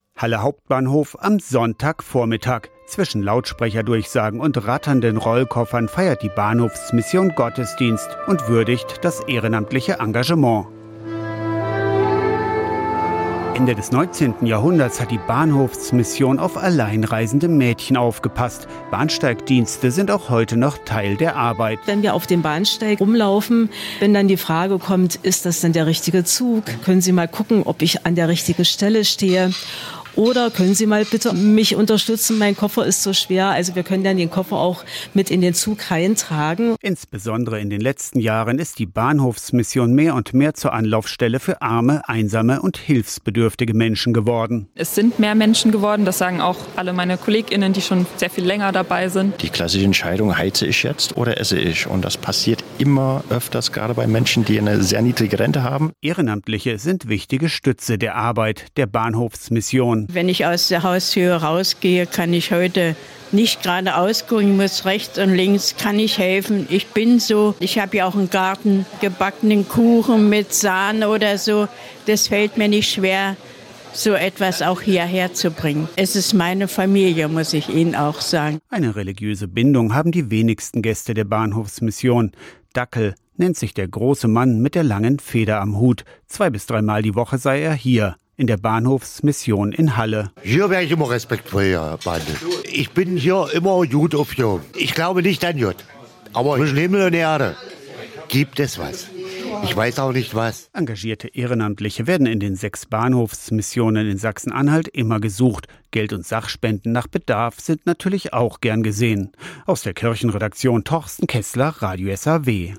iad-radio-saw-gottesdienst-zwischen-rollkoffern-und-bahnhofsdurchsagen-43111.mp3